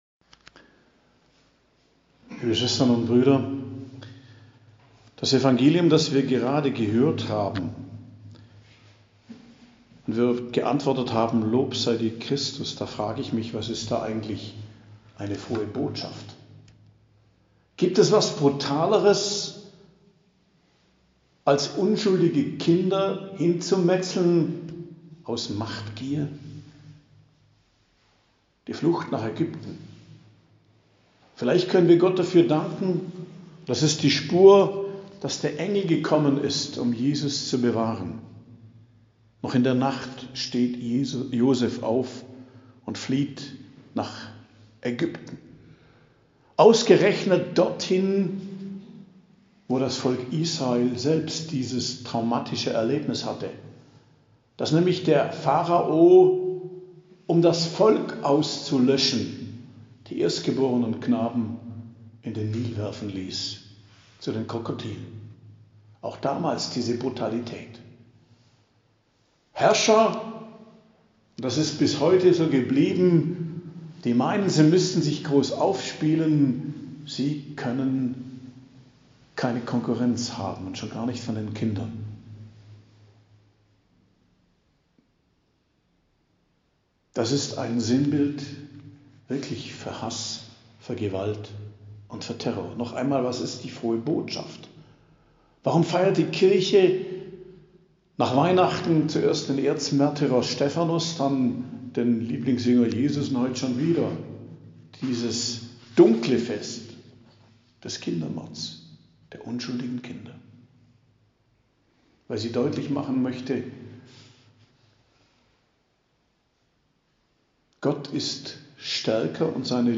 Predigt am Fest Unschuldige Kinder, 28.12.2022